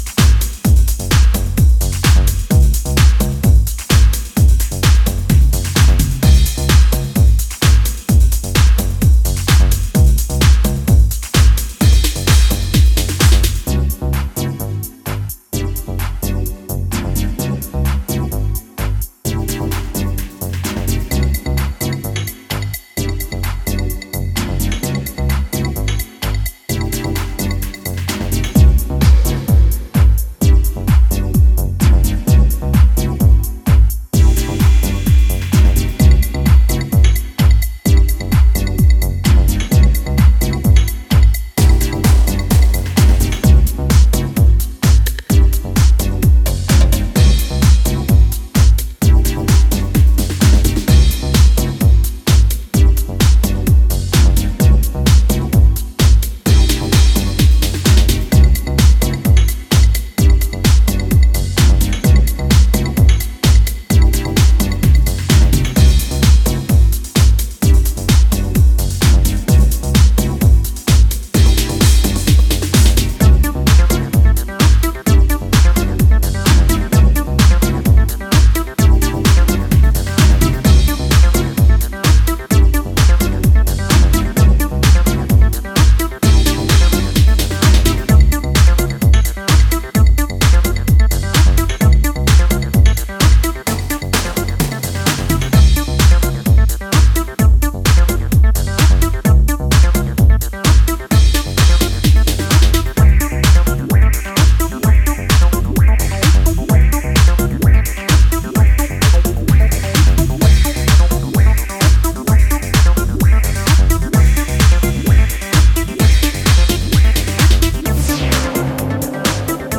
hopeful house